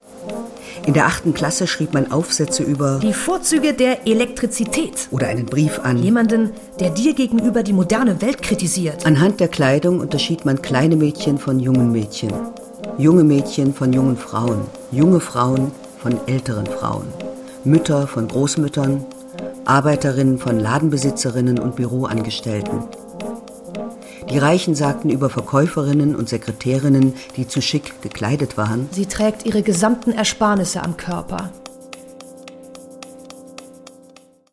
und lässt sie von vier Schauspielerinnen mit verschieden “jung” bzw. “älter” klingenden Stimmen vortragen. Ein Kniff, mit dessen Hilfe sozusagen alle Lebensphasen in jedem Moment dieses Lebens stimmlich präsent sind.